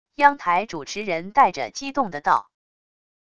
央台主持人带着激动的道wav音频